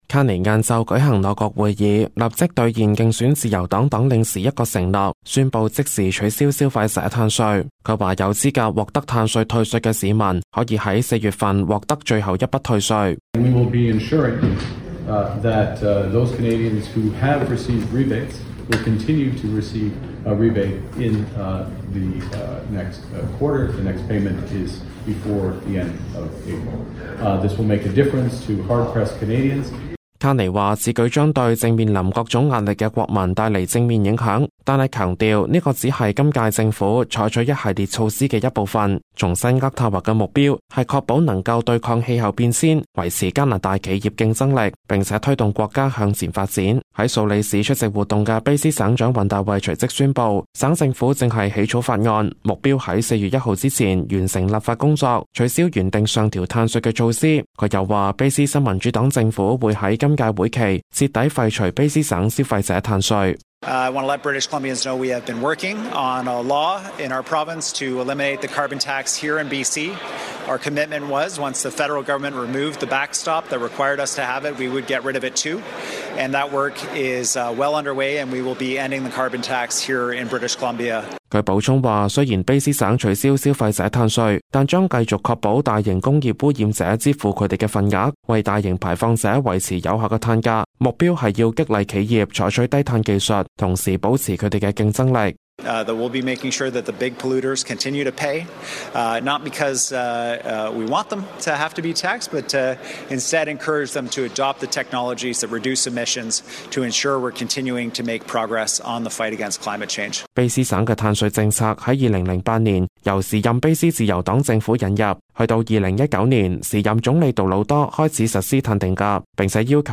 Canada/World News 全國/世界新聞
news_clip_22880.mp3